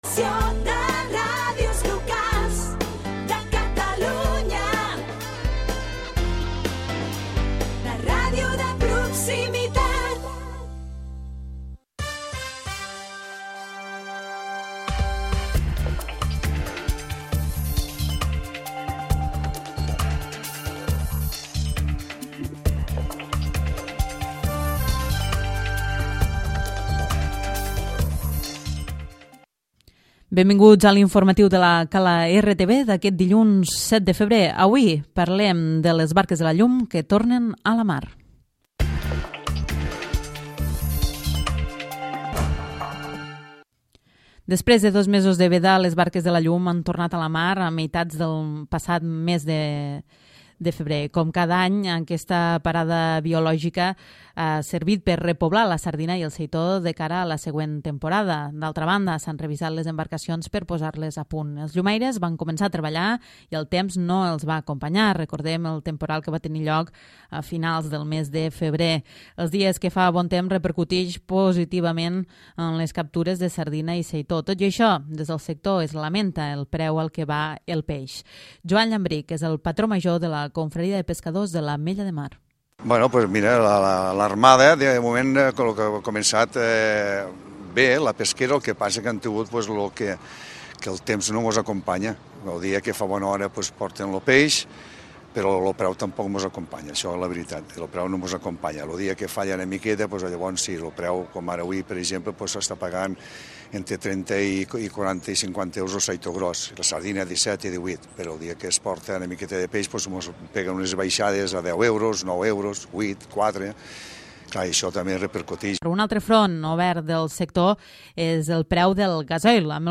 Butlletí informatiu